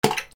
/ M｜他分類 / L30 ｜水音-その他
水にものを落とす
『ゴポ』